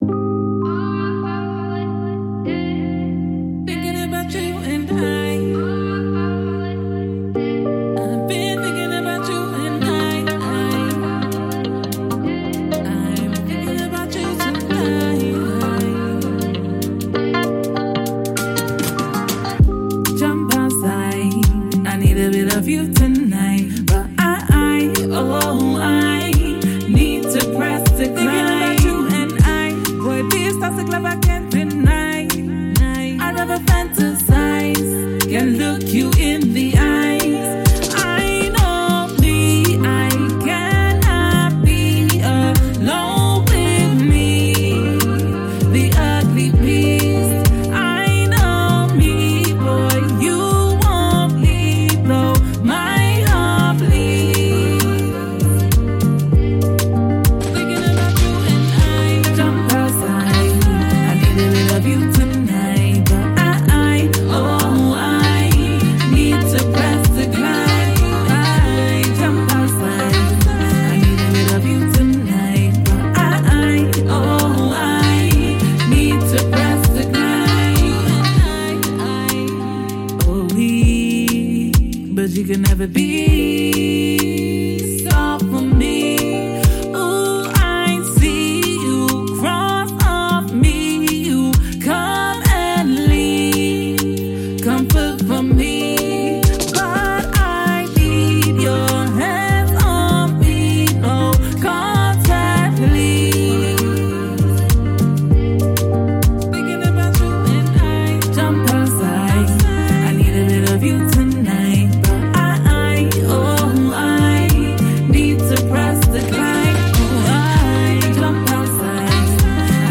Afrobeats, R&B, dancehall, and UK vibes
soulful Afro-R&B